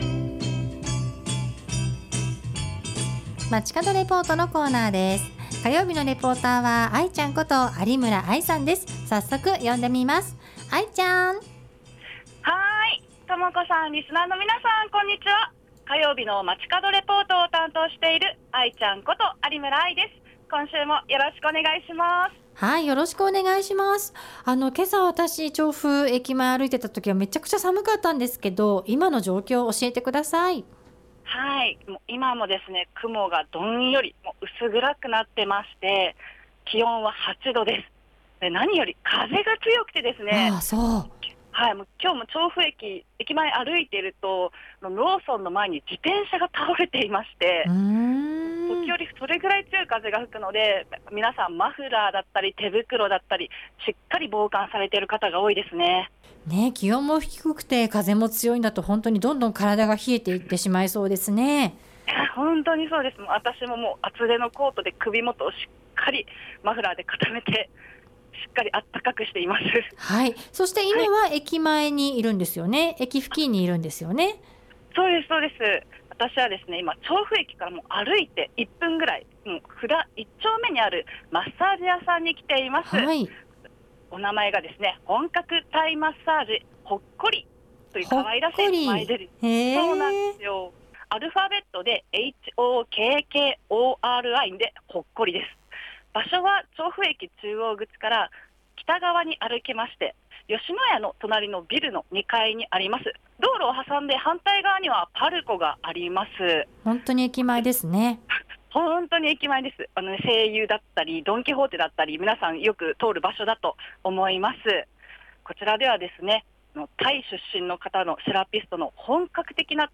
午後のカフェテラス 街角レポート
今週は本格的なタイマッサージを受ける事が出来る、「本格タイマッサージHokkori」からお届けしました！